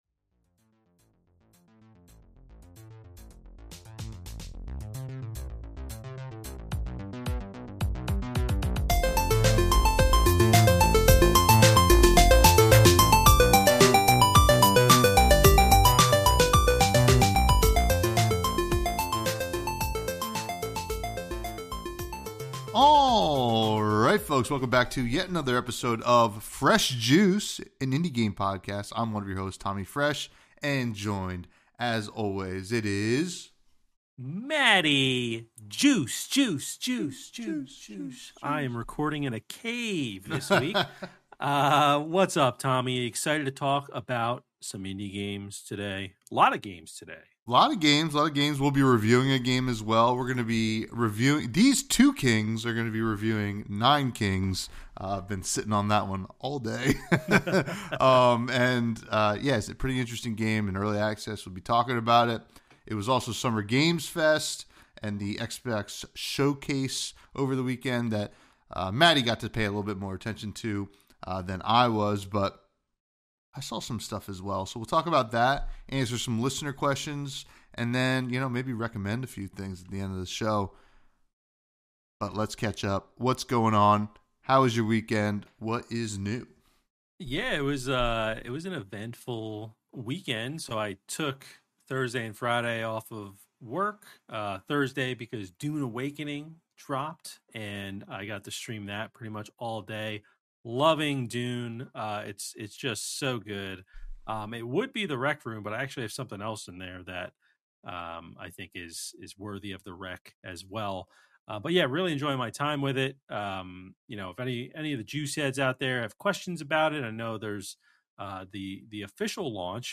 In each episode, we dive deep into the gameplay, mechanics, and artistry of various indie games. We also feature exclusive interviews with developers, sharing their stories and the inspirations behind their games.